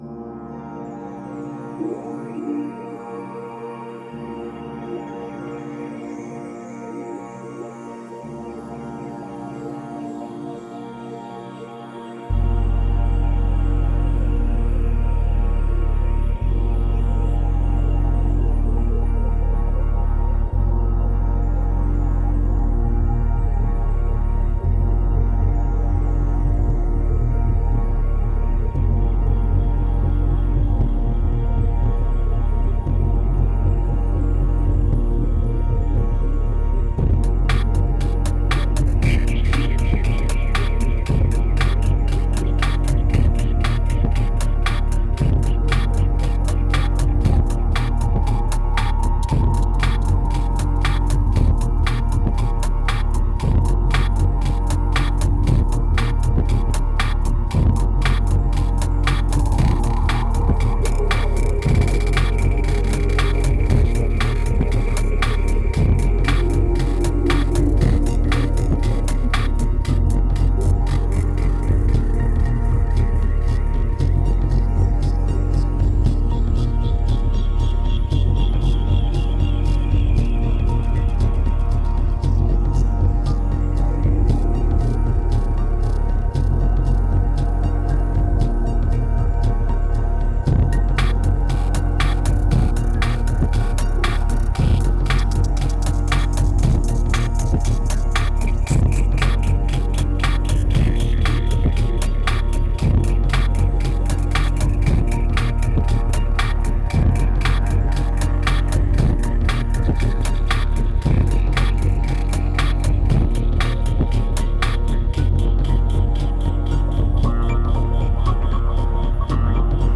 Pure remote desire, strong medication and raw electronica.